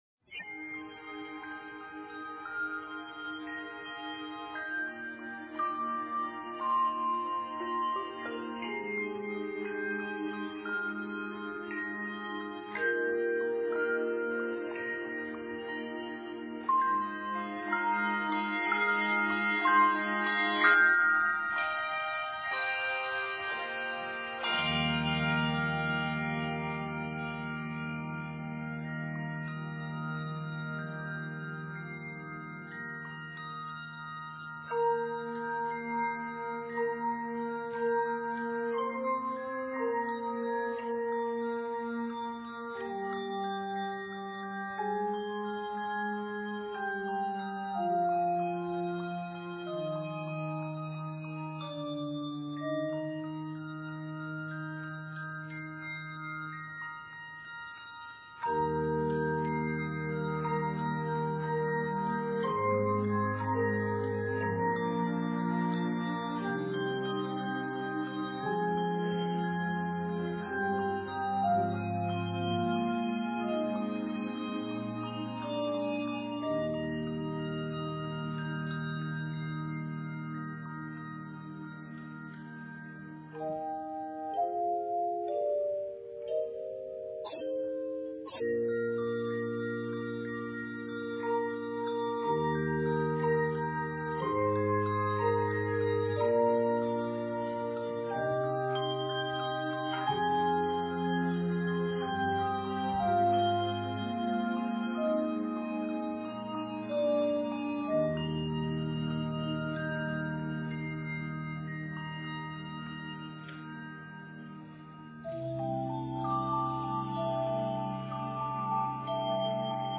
synthesizer string pad